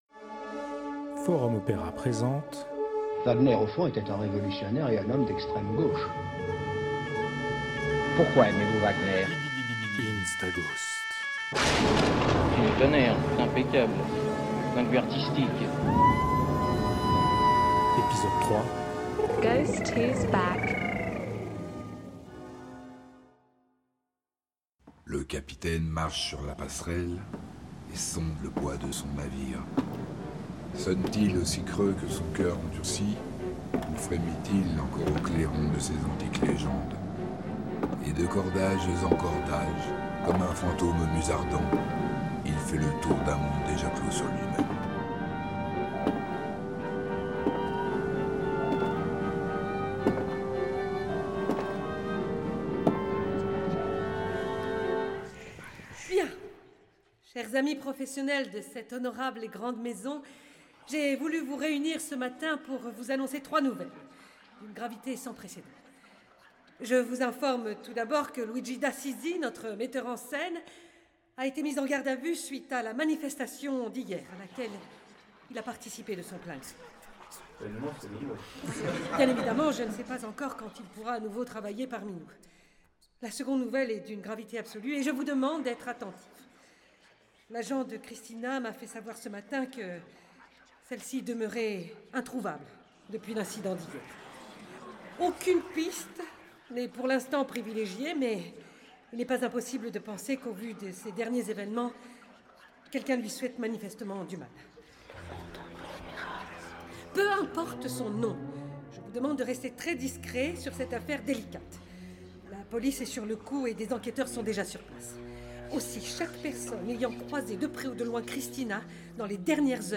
Instaghost – divertissement radiophonique, épisode 3 : Ghost who's back - Forum Opéra
Merci à MAB/Colligence Records pour le prêt du studio d’enregistrement.